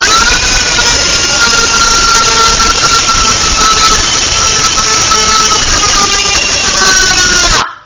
描述：一个女人在尖叫。
Tag: 666moviescreams 尖叫 女人